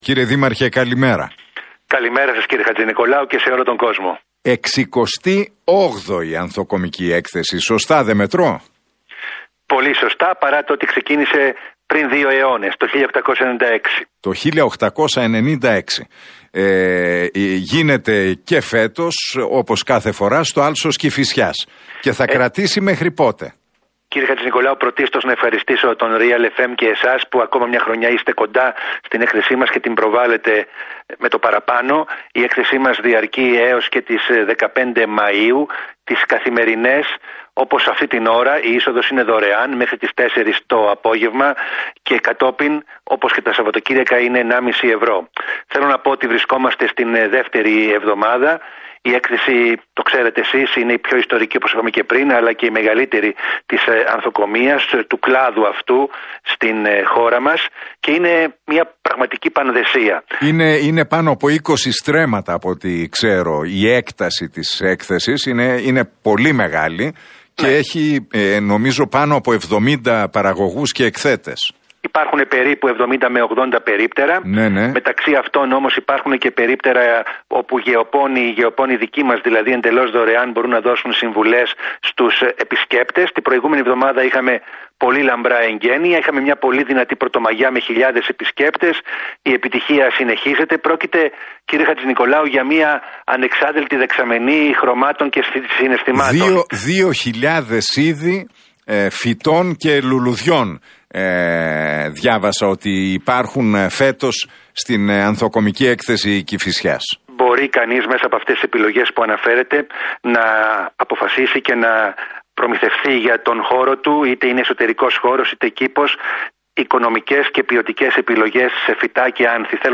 Ο Δήμαρχος Κηφισιάς, Γιώργος Θωμάκος, μιλώντας στον Realfm 97,8 και την εκπομπή του Νίκου Χατζηνικολάου, αναφέρθηκε στην Ανθοκομική έκθεση.